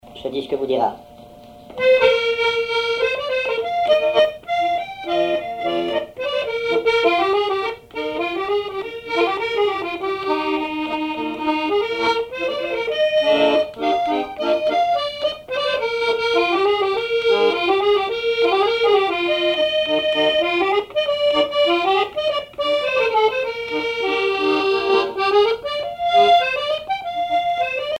Mémoires et Patrimoines vivants - RaddO est une base de données d'archives iconographiques et sonores.
accordéon(s), accordéoniste
danse : valse musette
Répertoire à l'accordéon chromatique
Pièce musicale inédite